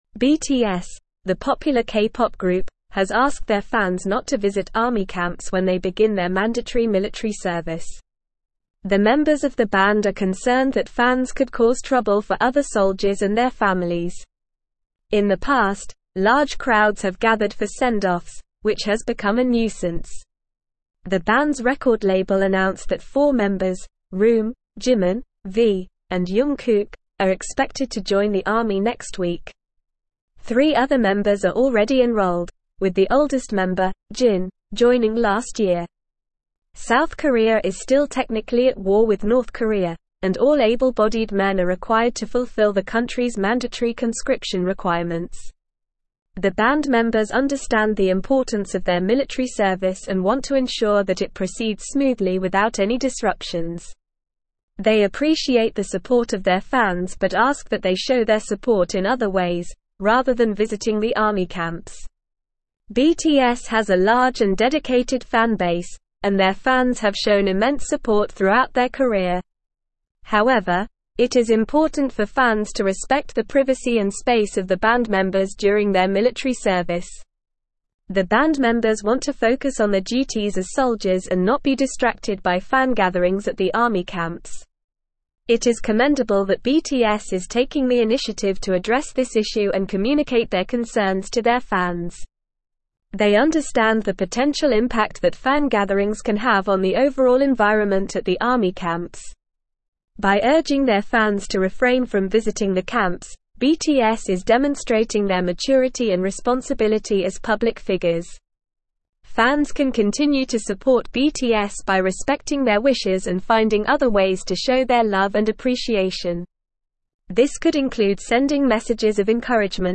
Normal
English-Newsroom-Advanced-NORMAL-Reading-BTS-Urges-Fans-to-Stay-Away-from-Army-Camps.mp3